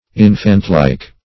\In"fant*like`\